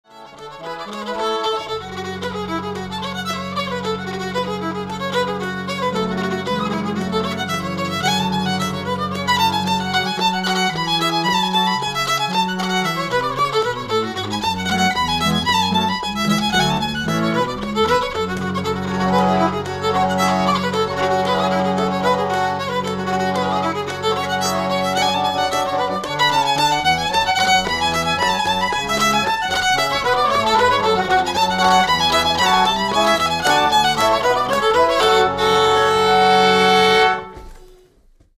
violin
pump organ